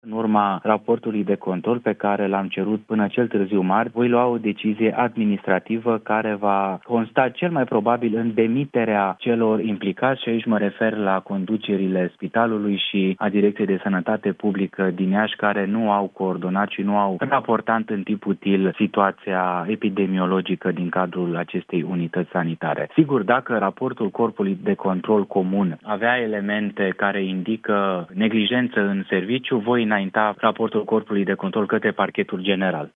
„Au gestionat cu nepăsare! Cel mai probabil, vor fi dați afară!”, a declarat la Europa FM ministrul Sănătății, Alexandru Rogobete, despre conducerea Spitalului de Copii „Sfânta Maria” din Iași, după ce șase copii au murit la secția ATI, infectați cu bacteria Serratia marcescens.